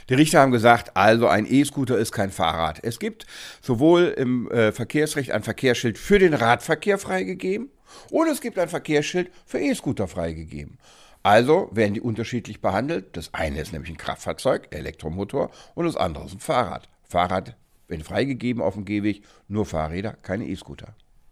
O-Ton: Kein E-Scooter auf dem Gehweg – Vorabs Medienproduktion